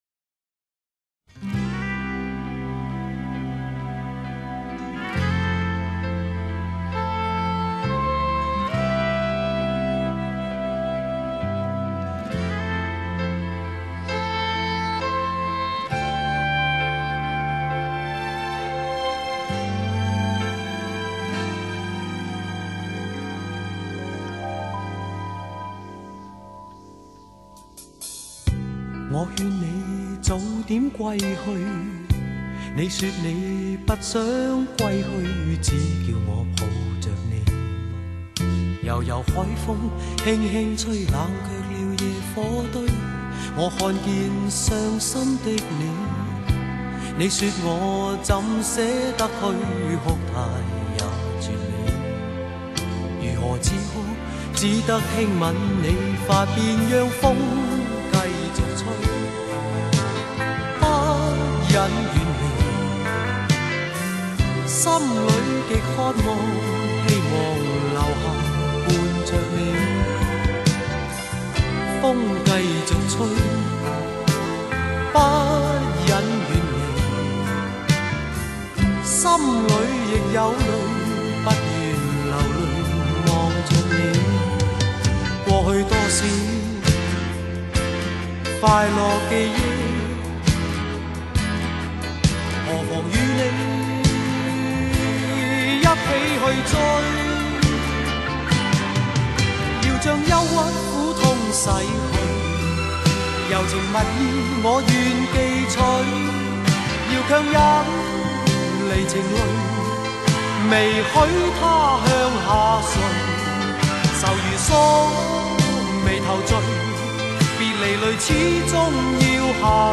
地区：香港
他的缓缓而低沉的慢曲极易打动人心，而且除了少数的几首外，大都不是靠优美的旋律，而是因为歌词的质朴和歌者的演绎。